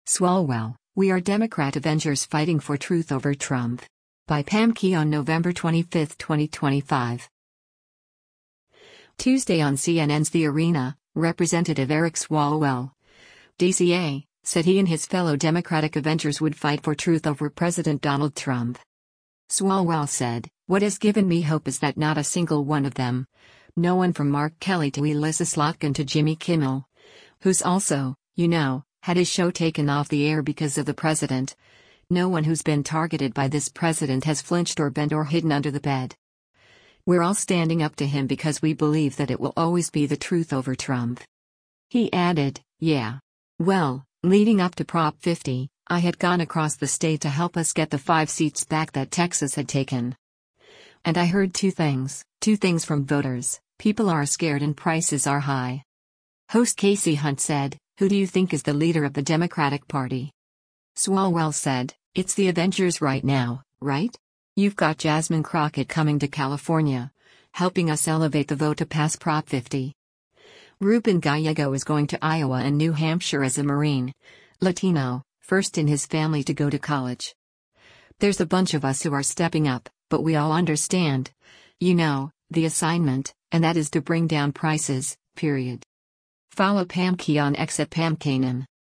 Tuesday on CNN’s “The Arena,” Rep. Eric Swalwell (D-CA) said he and his fellow Democratic “avengers” would fight for truth over President Donald Trump.
Host Kasie Hunt said, “Who do you think is the leader of the Democratic Party?”